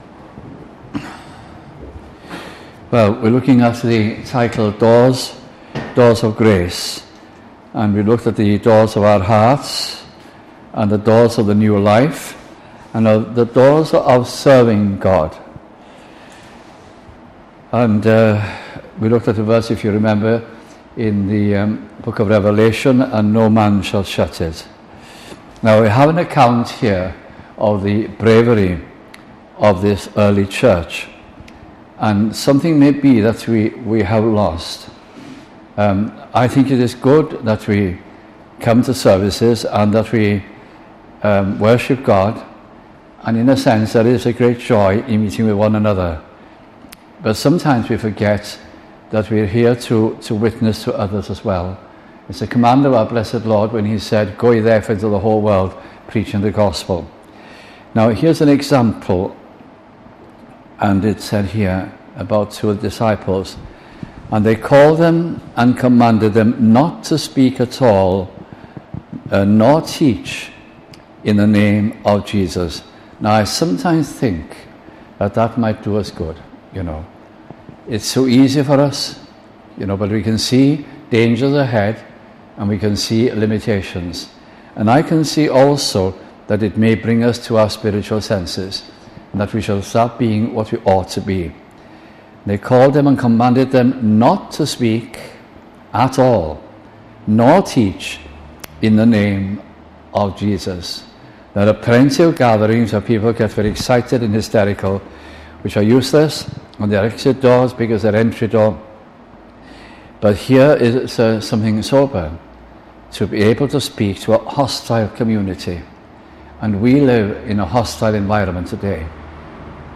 » Open Door » Young Adults » Chippenham Conference 2006